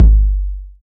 808 DIST K.wav